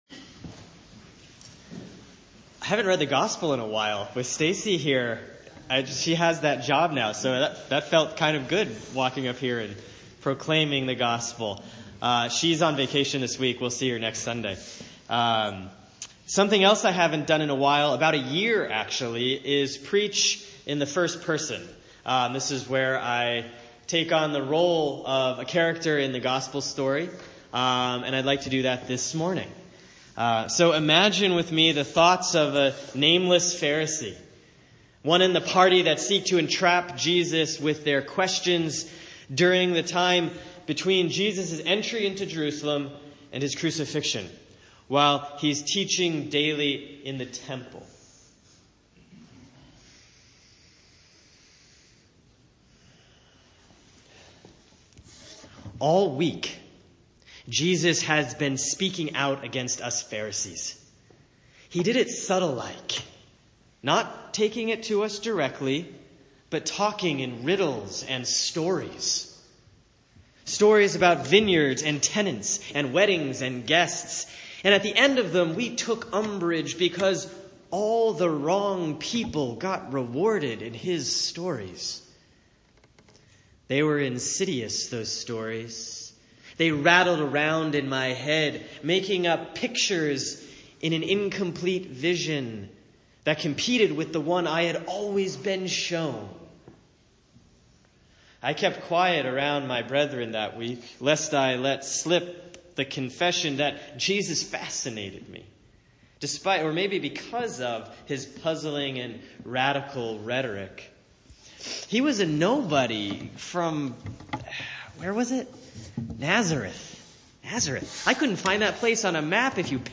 Sermon for Sunday, October 22, 2017 || Proper 24A || Matthew 22:15-22